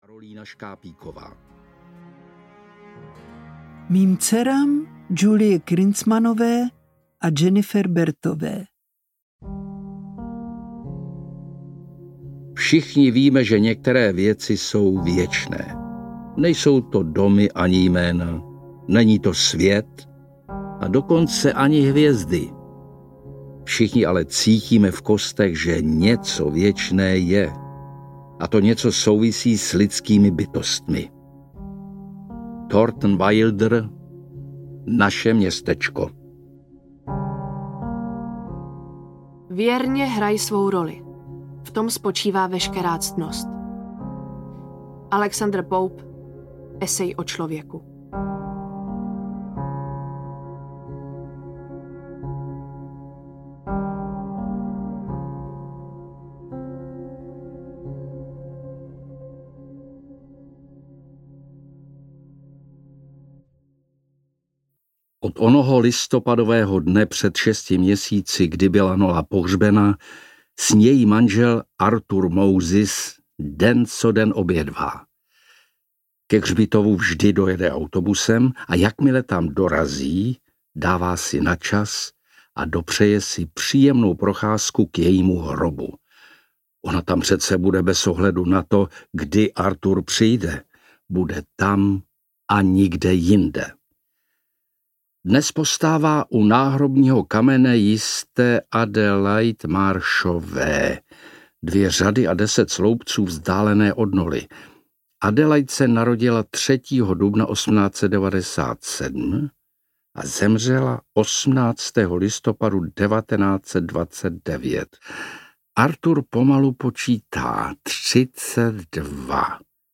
Příběh Arthura Truluva audiokniha
Ukázka z knihy
• InterpretLibuše Švormová, Denisa Barešová, Oldřich Vlach